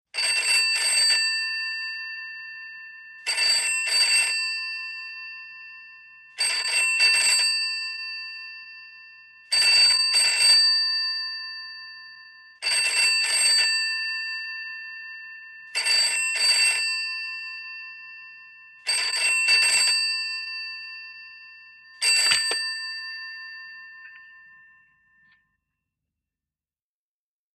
British desk telephone ringing, answered on 8th ring ( 1960`s )